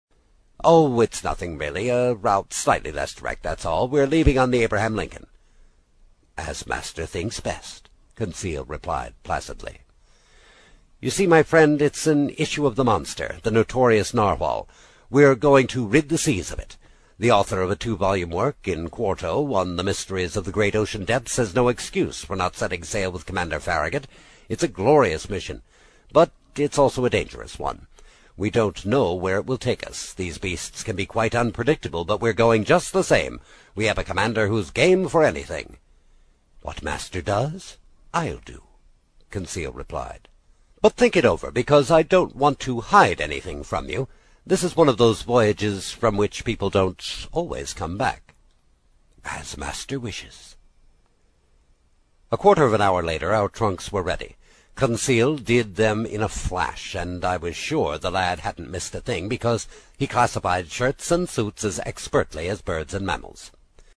在线英语听力室英语听书《海底两万里》第27期 第3章 随您先生的便(5)的听力文件下载,《海底两万里》中英双语有声读物附MP3下载